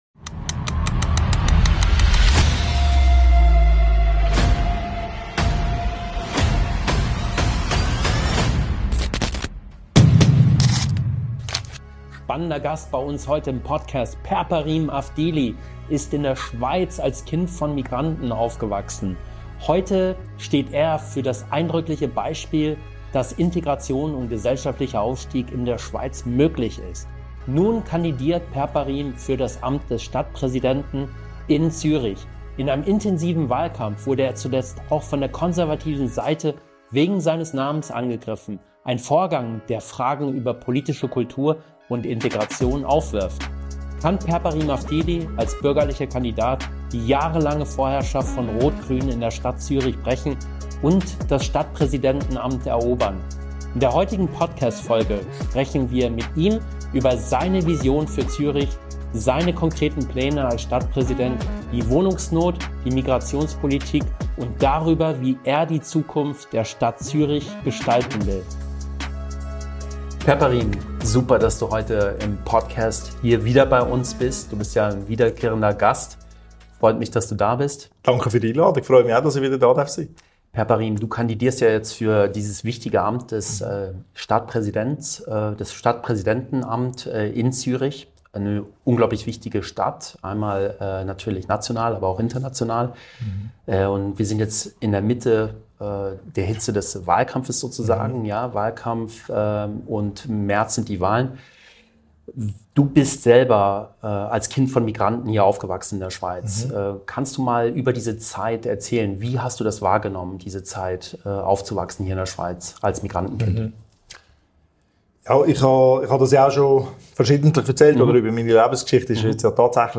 #99 - Interview mit Përparim Avdili - Stadtpräsidiumskandidat Zürich, Präsident FDP Stadt Zürich, Gemeinderat Zürich ~ CapricornConnect: People, Potential, Technology. Podcast
Beschreibung vor 1 Monat CapricornConnect mit Përparim Avdil: Aufstieg, Identität und die Zukunft von Zürich In dieser Episode von CapricornConnect sprechen wir mit dem Zürcher FDP-Präsidenten und Stadtpräsidentschaftskandidaten Përparim Avdili über seinen persönlichen Werdegang und seine politische Vision für Zürich.